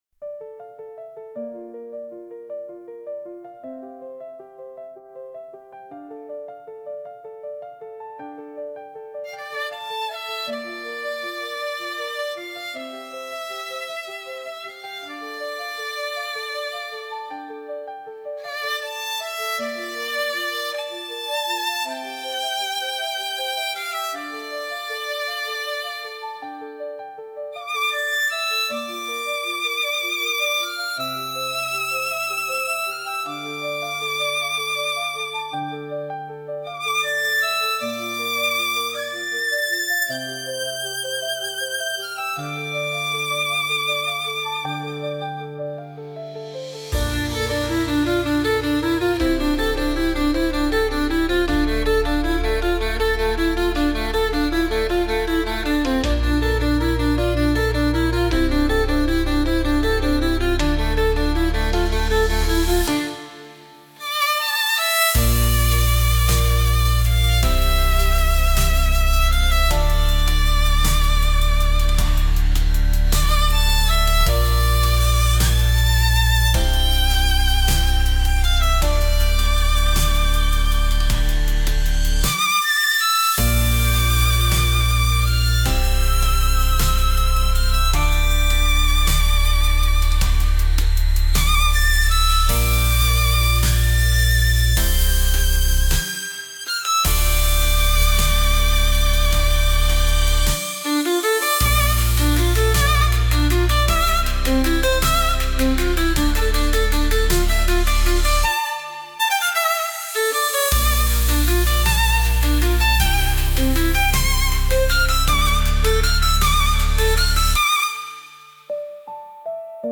piano-violine-guitar.mp3